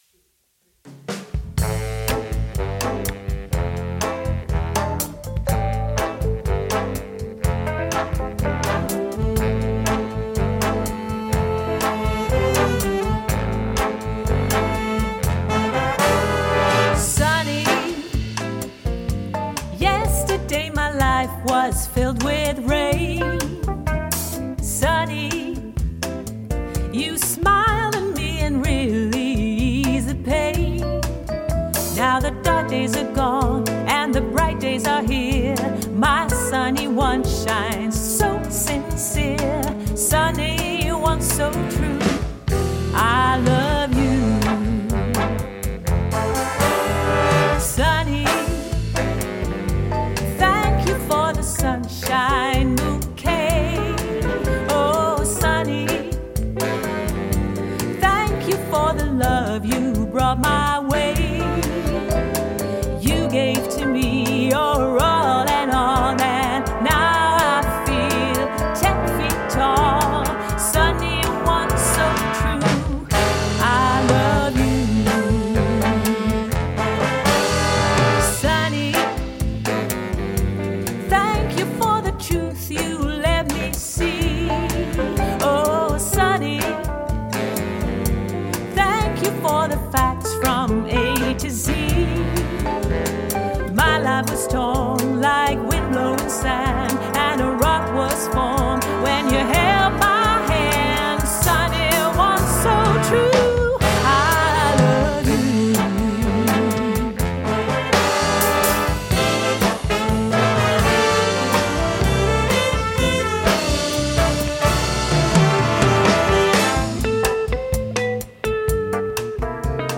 Pop/easylistening